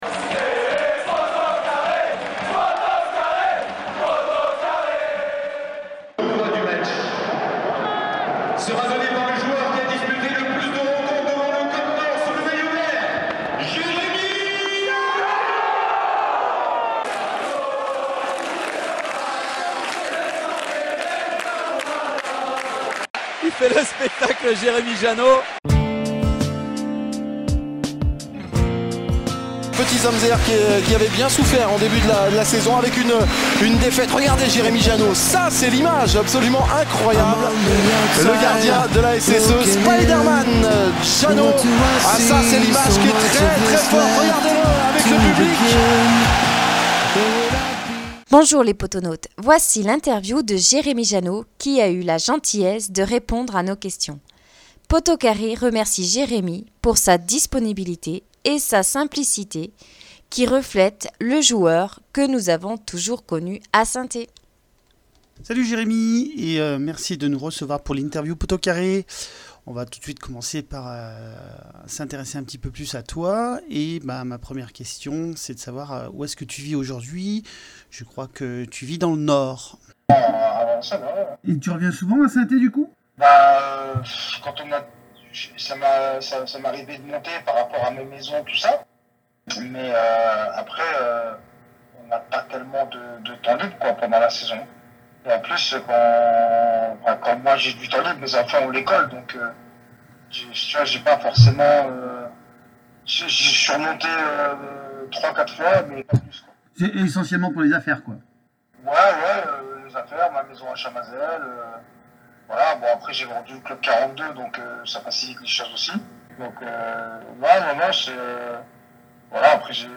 23:21 Jérémie Janot nous a accordé un entretien d'une heure à écouter intégralement ici .